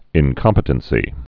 (ĭn-kŏmpĭ-tən-sē)